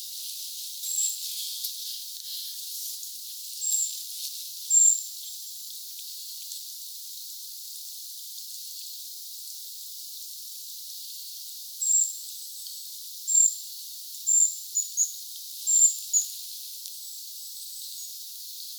tuollaista puukiipijälinnun siritysääntelyä
tuollaista_puukiipijalinnun_siritysaantelya.mp3